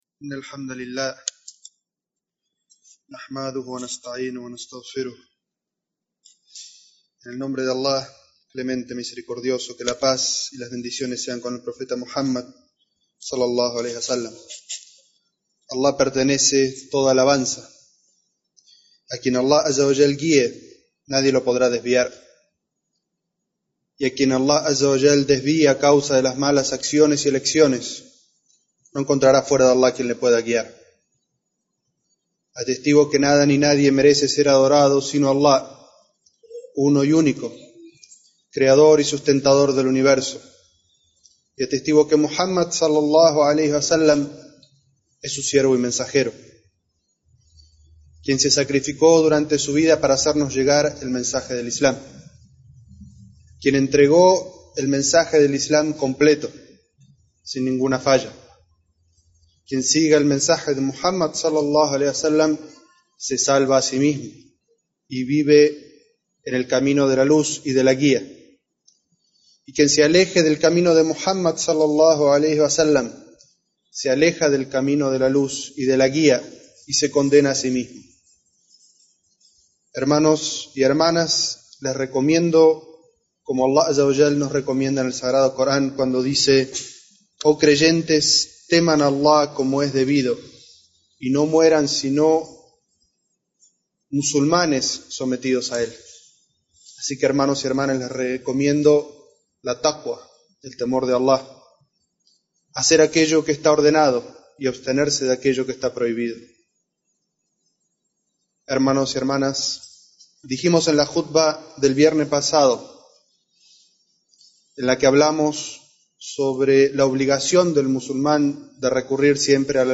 Jutbah Características de la ley de Allah